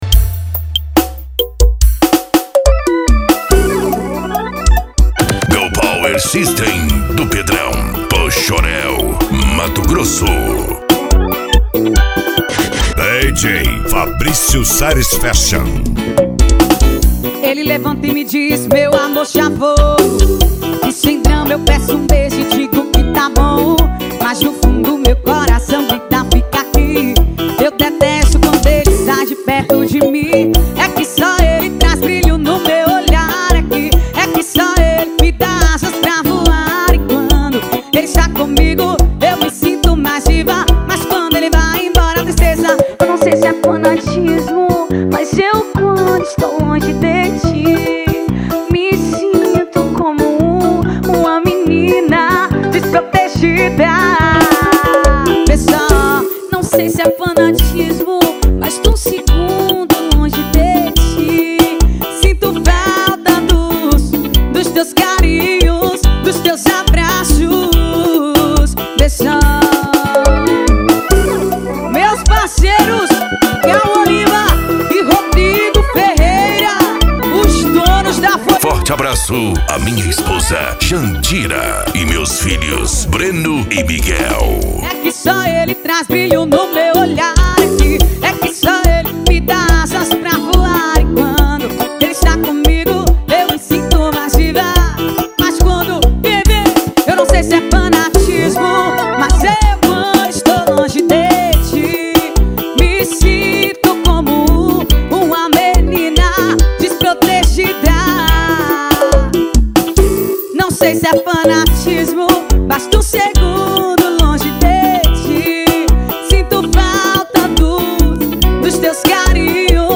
Funk
SERTANEJO